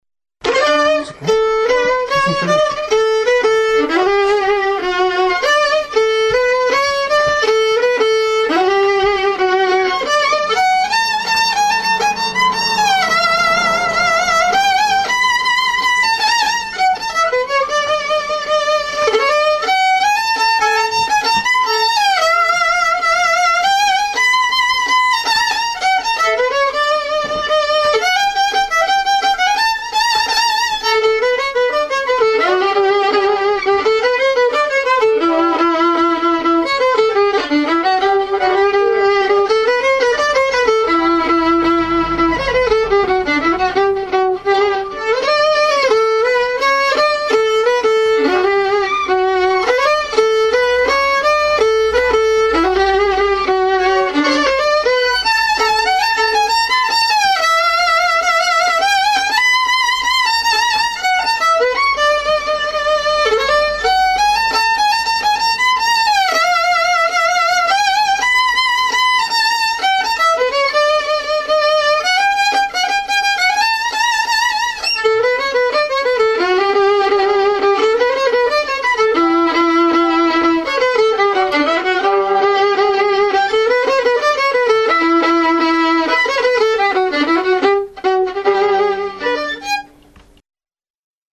Gra przede wszystkim na skrzypcach, ale również na „harmonii” (akordeonie) i organach.